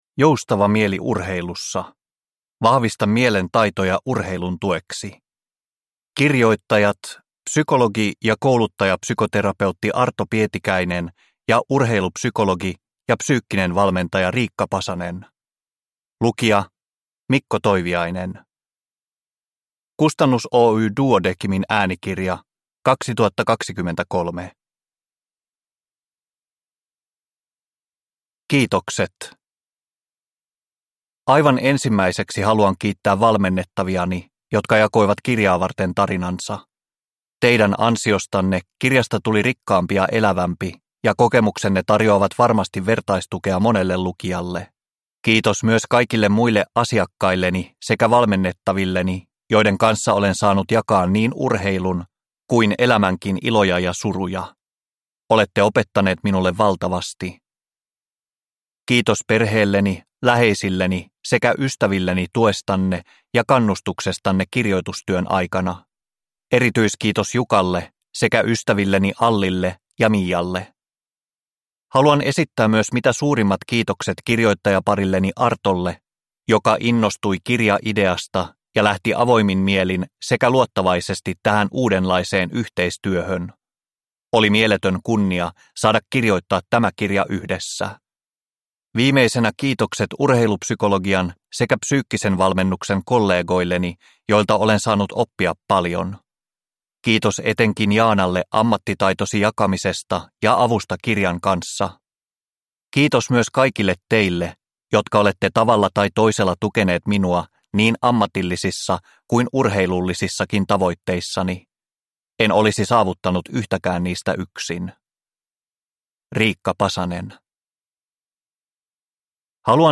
Joustava mieli urheilussa – Ljudbok – Laddas ner